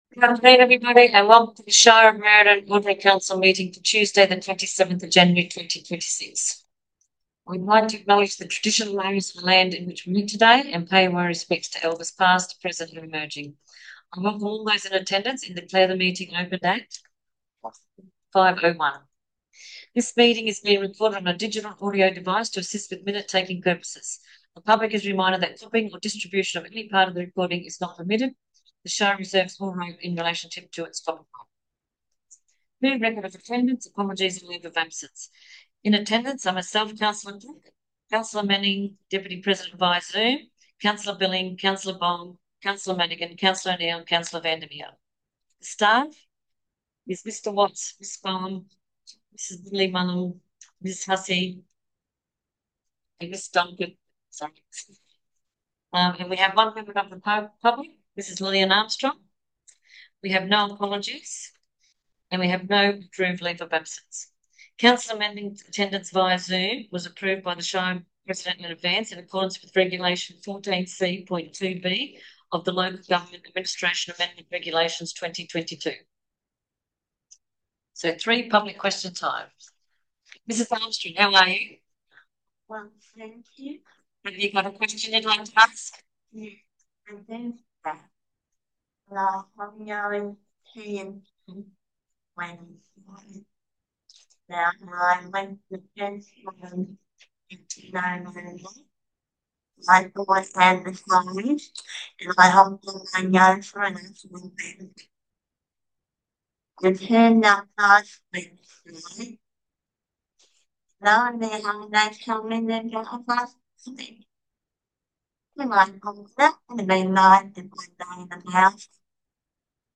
January Ordinary Council Meeting » Shire of Merredin